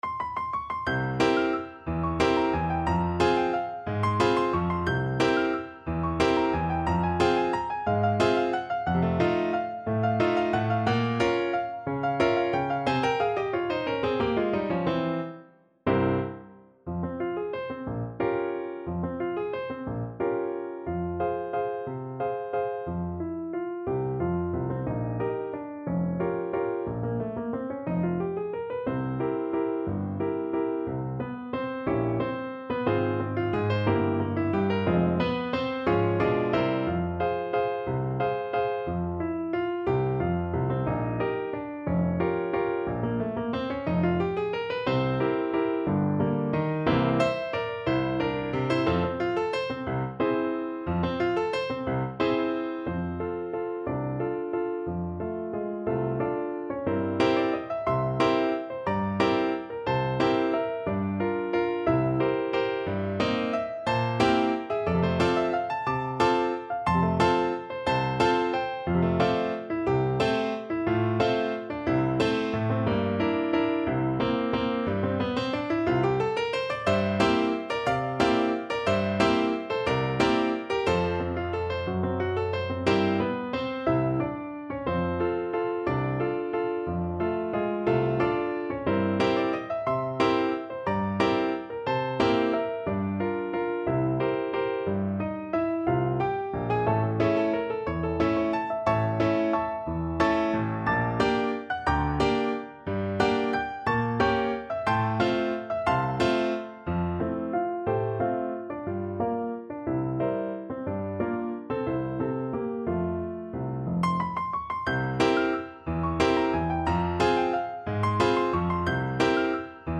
Allegro movido =180 (View more music marked Allegro)
3/4 (View more 3/4 Music)
Classical (View more Classical Clarinet Music)